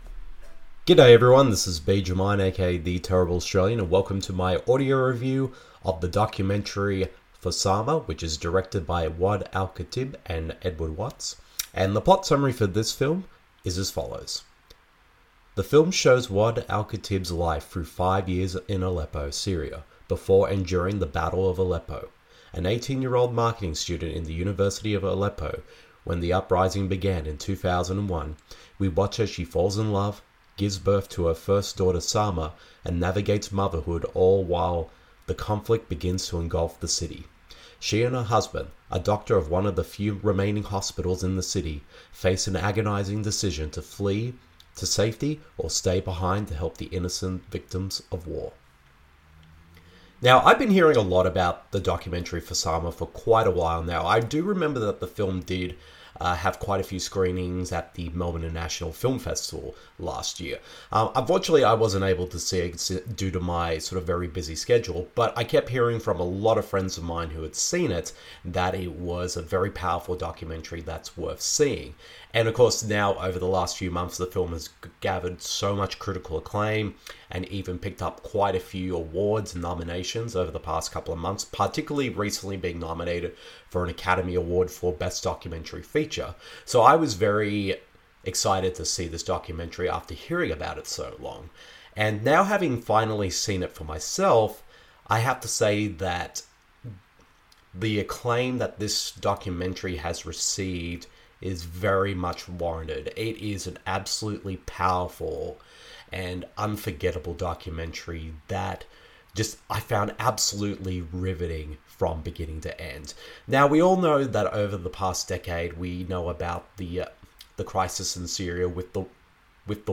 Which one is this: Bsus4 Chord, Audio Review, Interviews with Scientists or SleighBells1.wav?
Audio Review